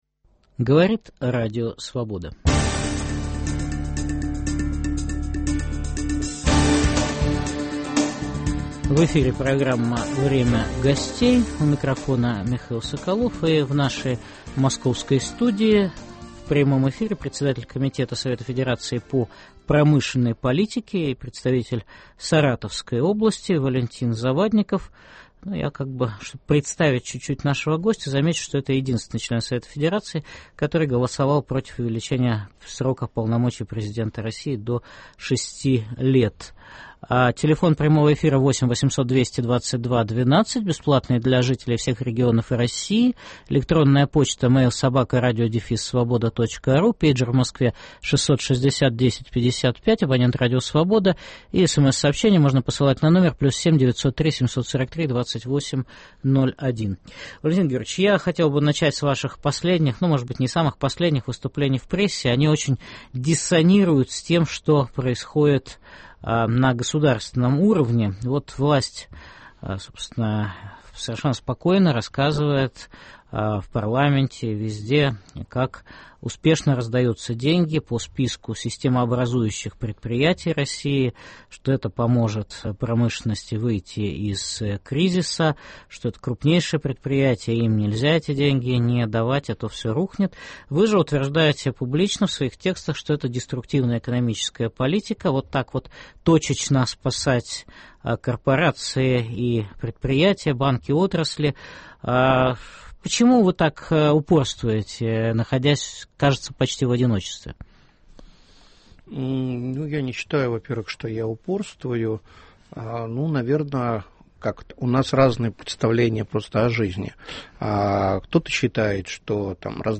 В прямом эфире обсуждаем планы поддержки российской индустрии в период кризиса. В студии - председатель комитета Совета Федерации РФ по промышленности Валентин Завадников.